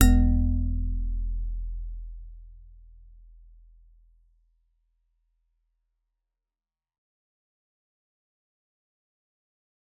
G_Musicbox-E1-f.wav